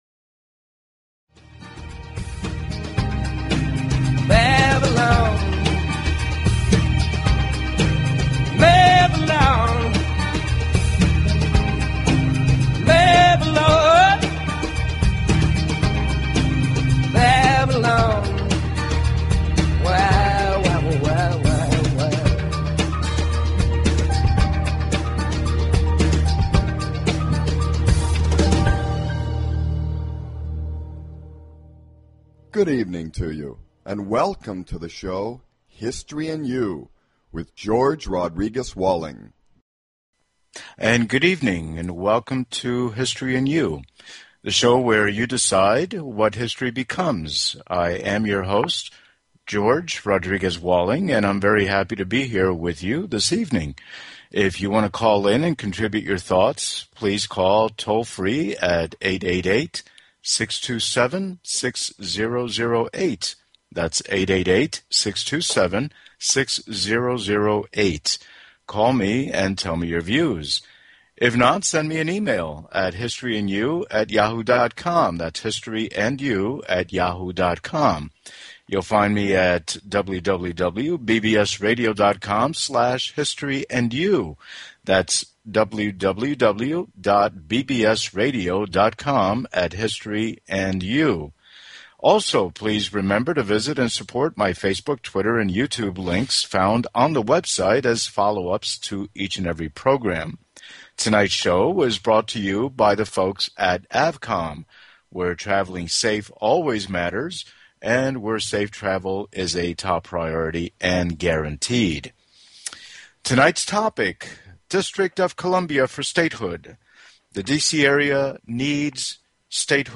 Guest, Michael Brown